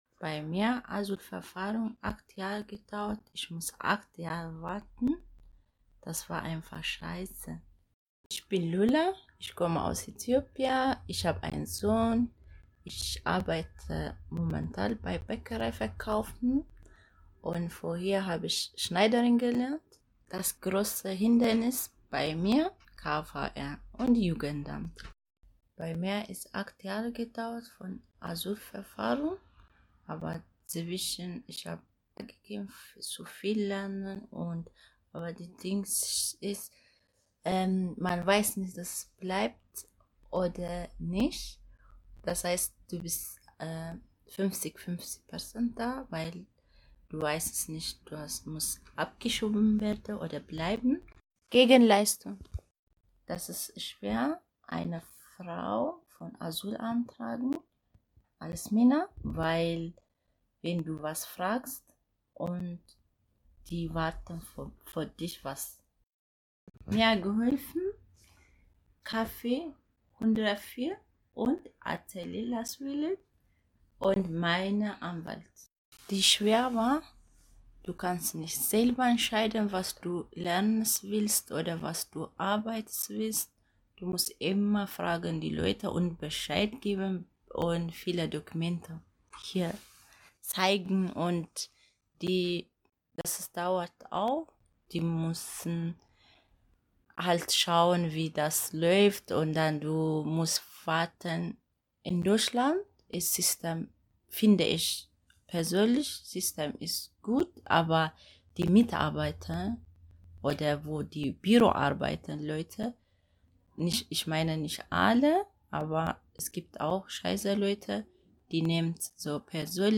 Junge Frauen aus München, mit und ohne Zuwanderungsgeschichte, beschrieben in sehr persönlichen Worten die Bedeutung von Bildung und Berufsausbildung für ihre Biografie.
Produziert wurden die O-Töne speziell für das Fachforum.